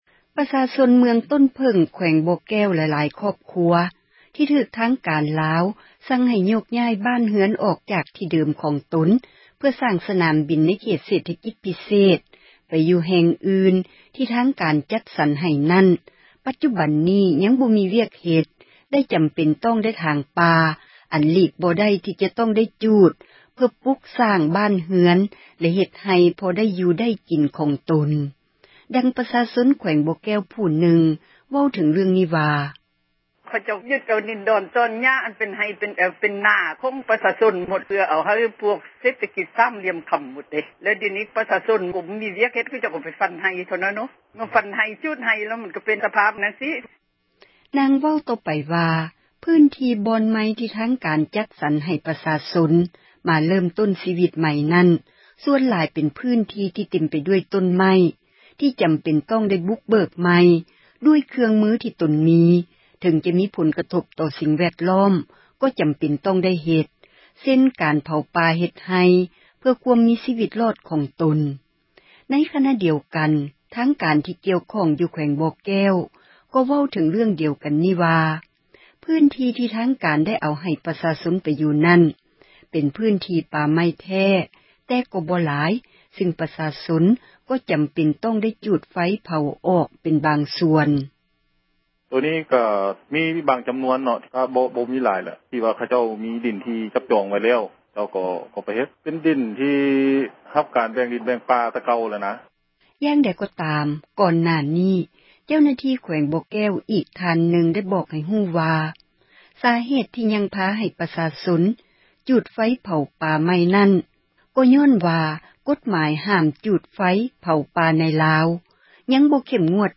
ປະຊາຊົນ ໃນເມືອງ ຕົ້ນເຜີ້ງ ແຂວງບໍ່ແກ້ວ ທີ່ຖືກ ທາງການລາວ ສັ່ງໃຫ້ໂຍກຍ້າຍ ອອກຈາກ ທີ່ເດີມ ຂອງຕົນ ເພື່ອສ້າງ ສນາມບິນ ໃນເຂດ ເສຖກິດພິເສດ ໄປຢູ່ແຫ່ງອື່ນ ທີ່ທາງການ ຈັດສັນ ໃຫ້ນັ້ນ ປັດຈຸບັນ ຍັງບໍ່ມີ ວຽກເຮັດ ແລະ ຈໍາເປັນຕ້ອງໄດ້ ຖາງປ່າ ຈູດປ່າ ເພື່ອປຸກສ້າງ ບ້ານເຮືອນ ເຮັດໄຮ່ ພໍໄດ້ຢູ່ໄດ້ກິນ. ດັ່ງຊາວບໍ່ແກ້ວ ຜູ້ນຶ່ງ ເວົ້າວ່າ: